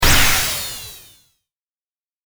ビーム発射音.mp3